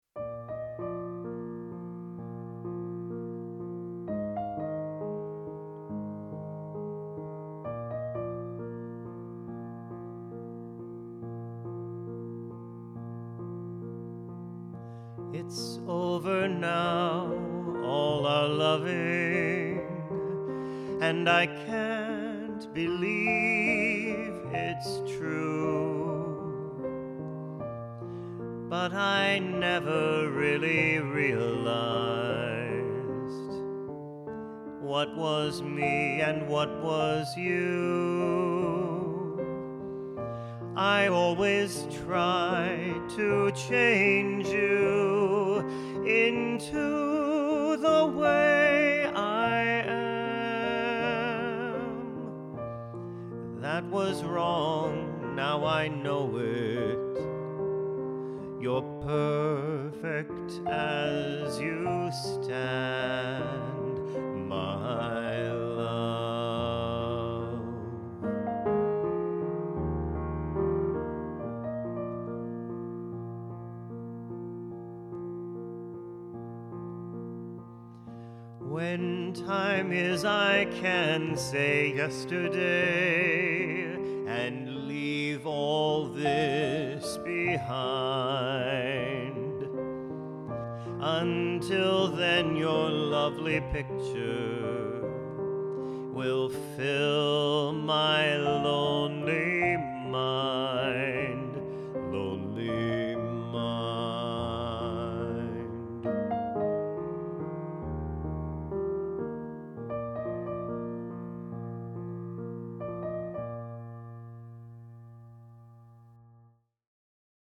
It's Over Now All Our Loving (Vocals